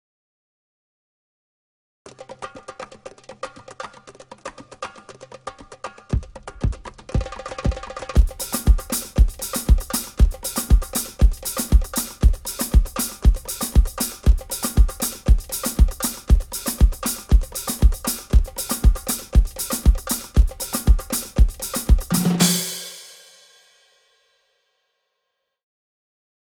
TABLATURE format GUITAR PRO + Backing Tack music 118 BPM.